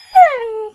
mob / wolf / whine / zombie.ogg